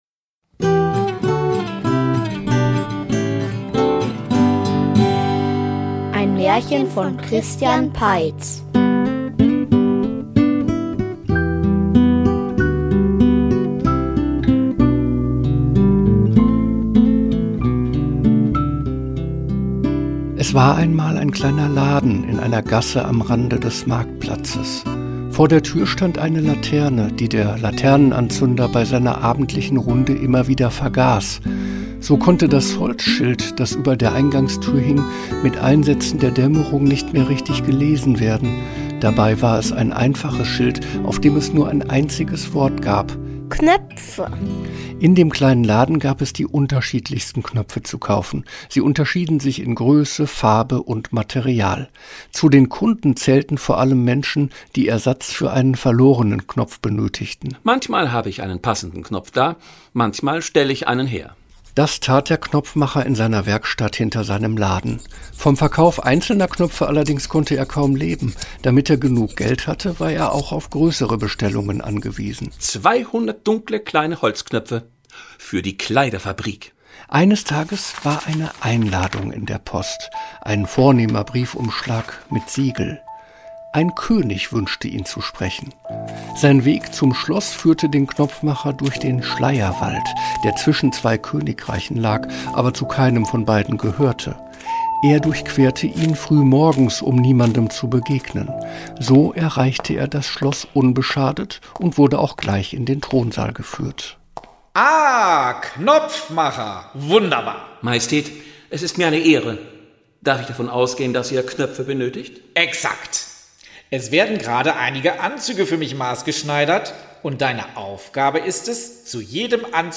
Der Knopfmacher --- Märchenhörspiel #55 ~ Märchen-Hörspiele Podcast